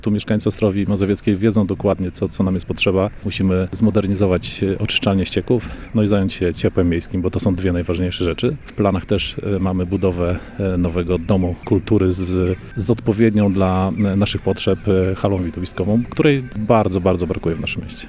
Burmistrz zaznacza, że pierwszy rok zamierza poświęcić na wdrożenie się i przygotowanie do realizacji punktów programu wyborczego.